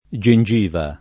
gengiva [ J en J& va ]